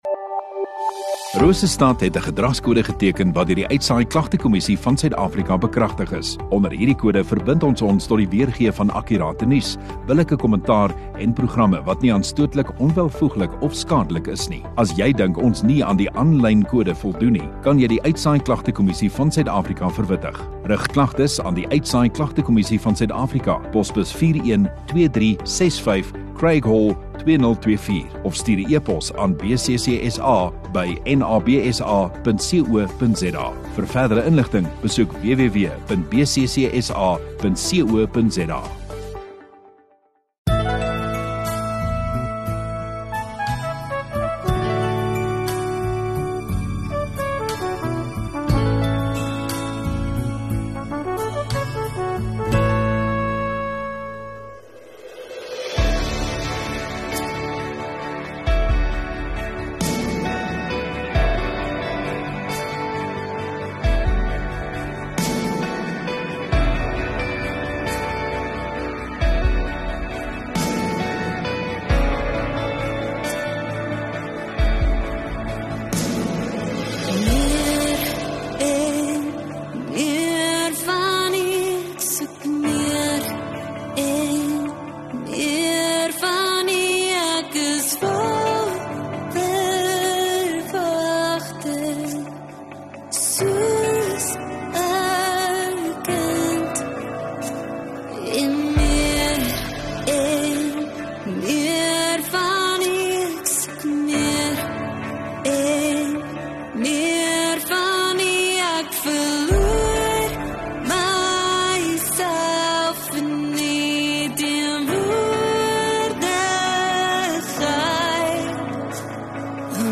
8 Dec Sondagoggend Erediens